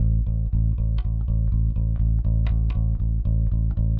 Live Bass Guitar Loops " Bass loop 120 bpm rock eights a
描述：小套的贝斯循环典型的摇滚八音，速度为120 bpm不同的音符（在文件名后面）。循环完美。有压缩器的线型低音信号。指点江山。
Tag: 低音吉他 手指 摇滚 现场 吉他 120BPM 八分 低音 不断